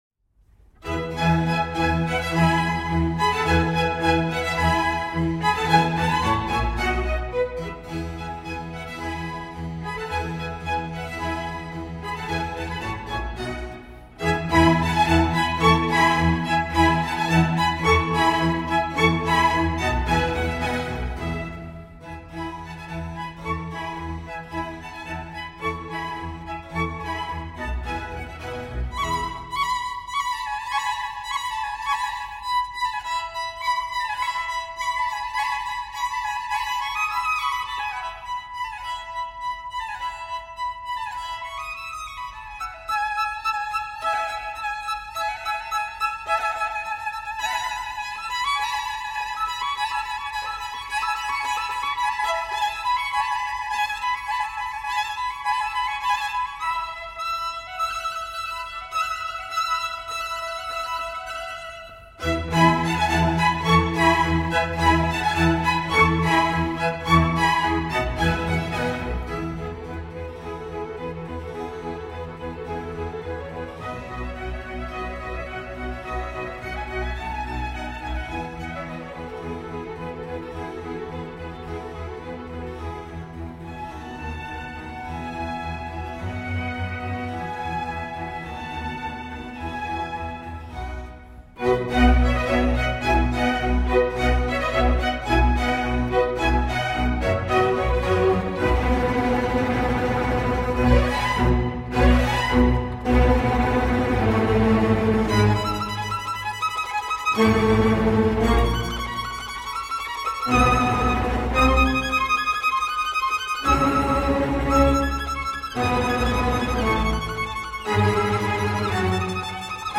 موسیقی کلاسیک(شماره 3)